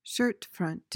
PRONUNCIATION:
(SHUHRT-fruhnt)